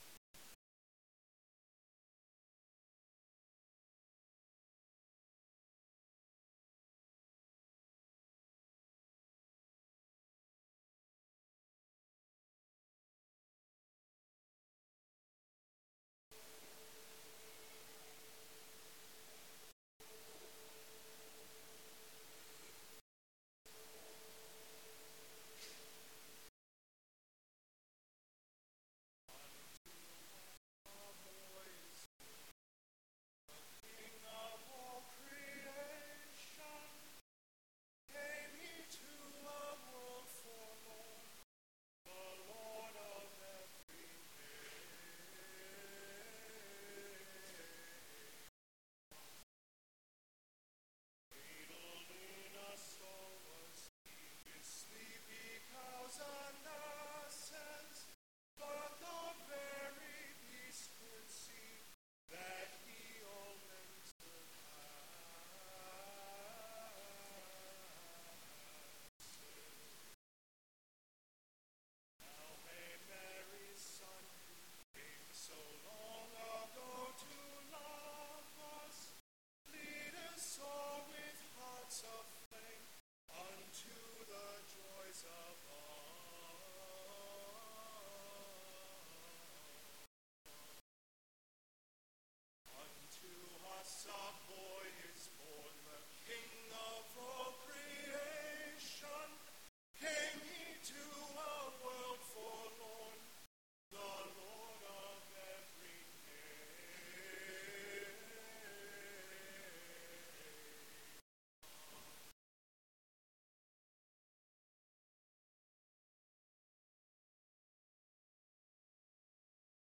Carol Concert December 2018
Music is a very important part of our worship and common life at St. Benedict’s. We have a small, talented choir which attracts professional as well as avocational musicians.
The style of music is quite eclectic, ranging from classical well-beloved songs and hymns to those composed in the twentieth century as part of the liturgical revival.
CarolConcert2018.mp3